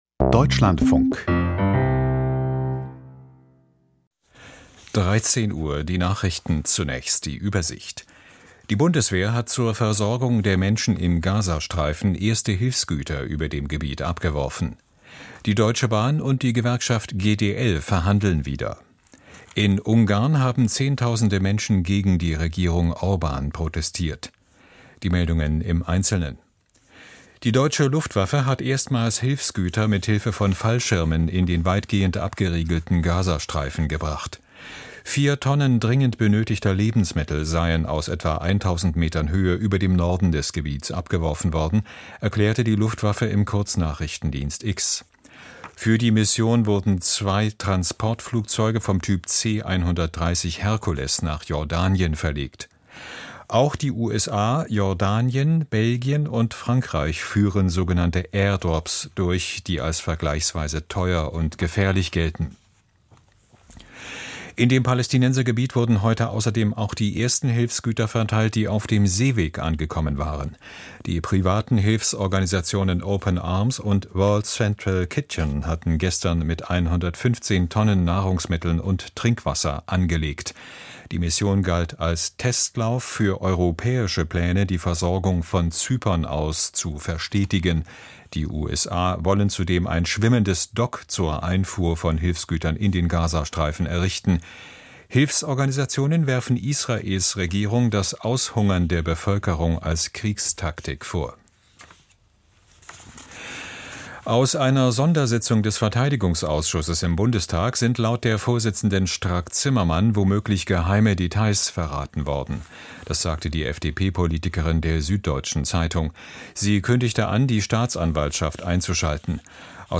Deutschlandfunk - DLF LIVE - Deutschlandfunk LIVE